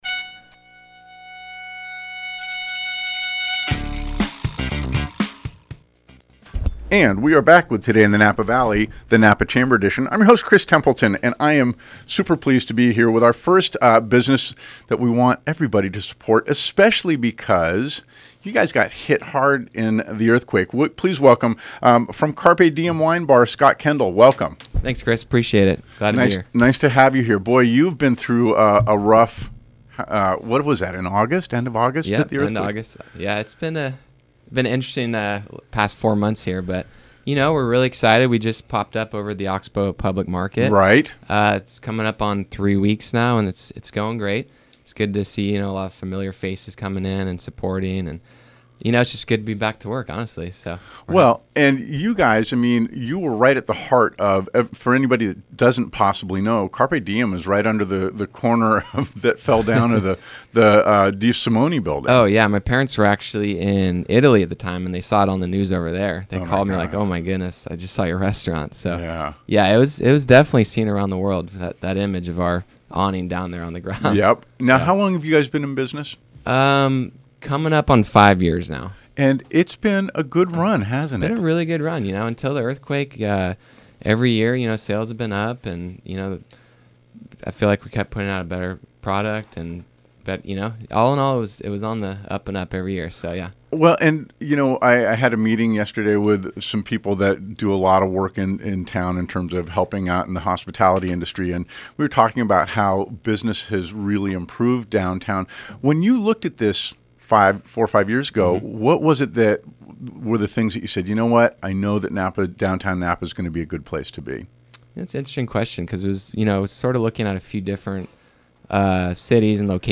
Napa Chamber KVON Radio Interview